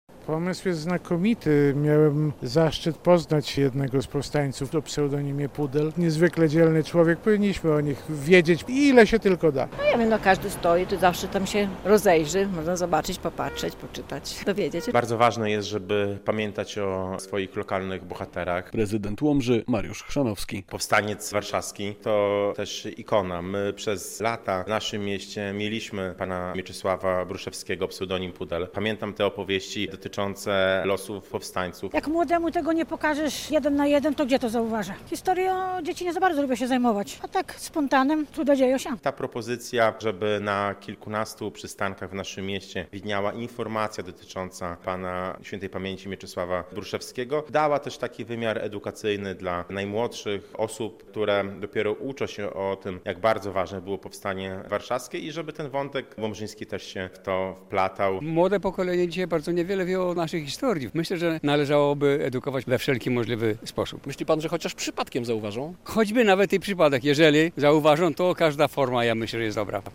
relacja
To bardzo dobra forma edukacji - mówią mieszkańcy Łomży.
Prezydent Łomży Mariusz Chrzanowski mówi, że pomysł akcji bardzo mu się spodobał.